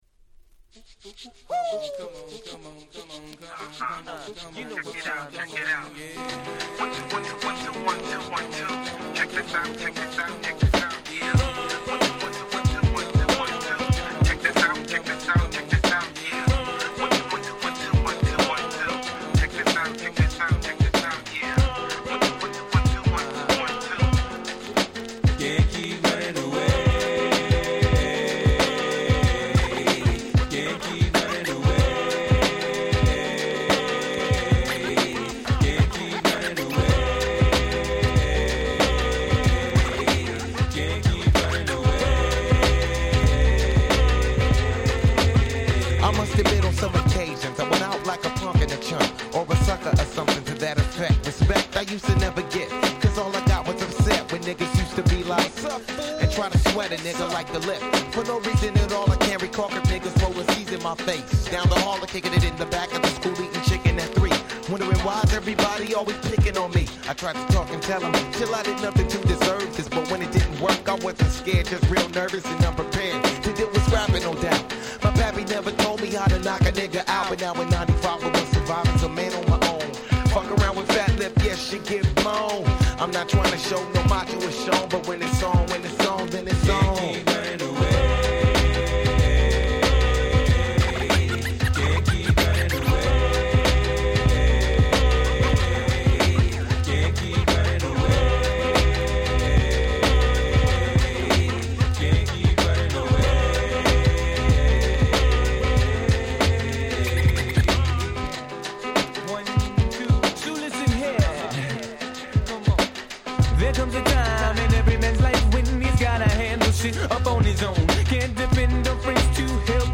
95' Big Hit Hip Hop.